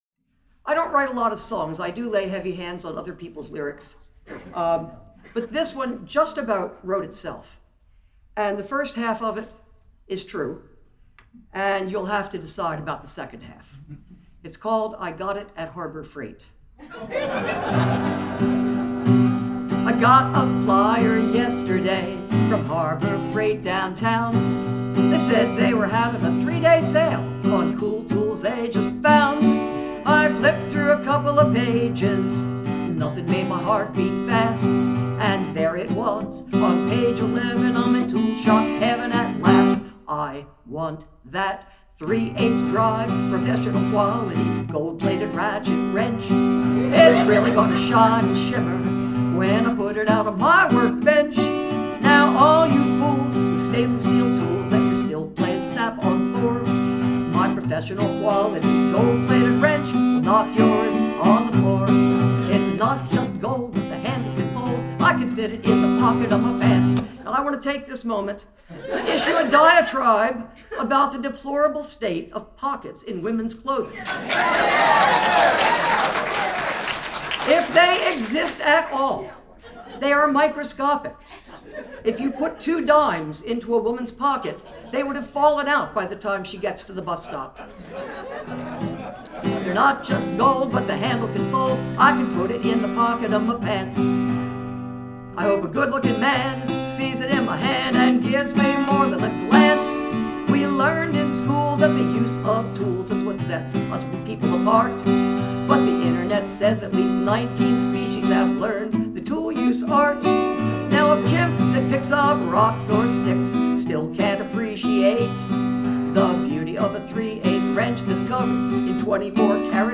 2025 Concert Recordings  - Wisdom House, Litchfield, CT